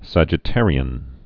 (săjĭ-târē-ən)